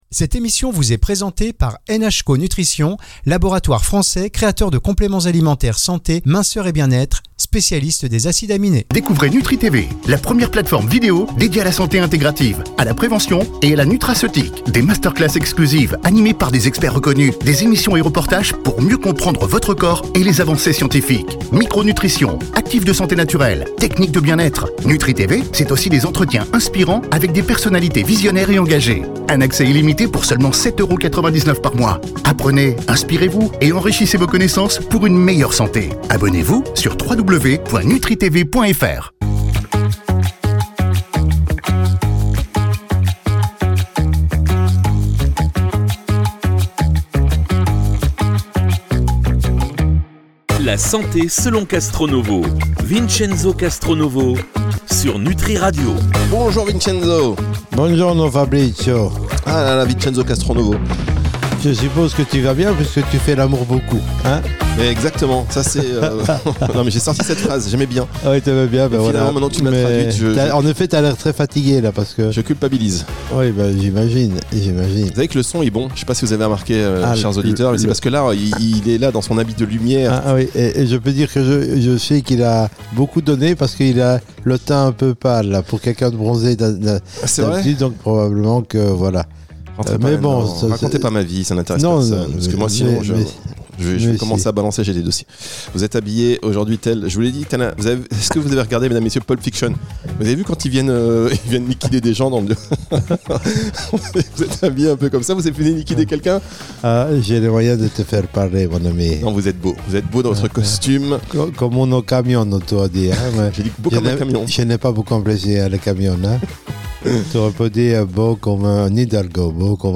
Entre éclats de rire et explications limpides, on découvre pourquoi l’hyperinsulinisme est au cœur de nombreuses pathologies modernes et comment notre évolution — jusqu’à l’époque de l’esclavage — influence encore aujourd’hui notre manière de stocker le sucre. Un échange vif, accessible et instructif sur les liens entre biologie, alimentation et histoire humaine.